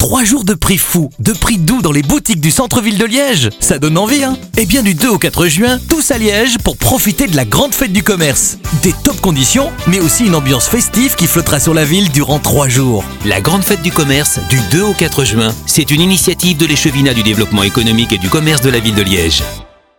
spot radio « Grande Fête du Commerce » de 20 secondes diffusé du 28 mai au 4 juin sur Bel Rtl (7 spots/jour), Contact (7 spots/jour), Nostalgie (6 spots/jours) et Maximum (7spots/jour).
Spot radio GFC.mp3